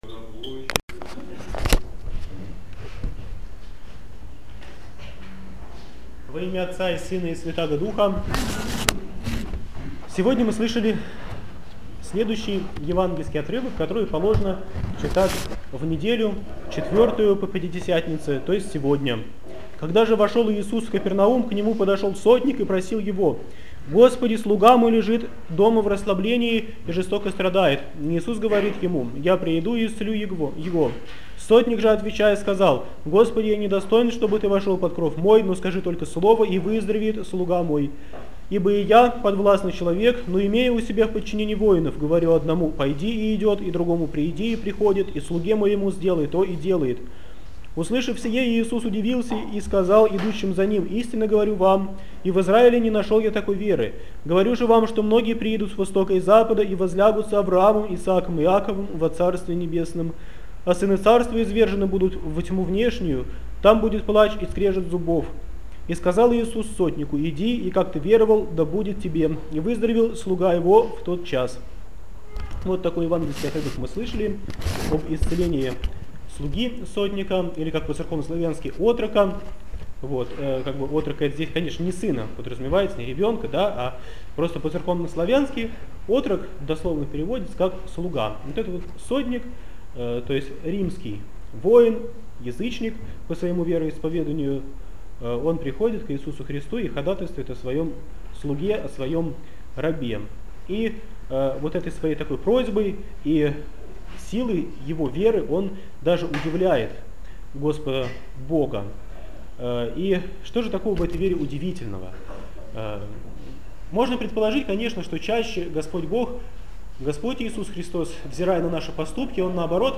БОГОЛЮБСКИЙ ХРАМ ПОСЕЛОК ДУБРОВСКИЙ - Проповедь в Неделю 4-ю по Пятидесятнице, об исцелении слуги сотника, от Матфея 2013